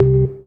ORGAN-15.wav